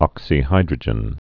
(ŏksē-hīdrə-jən)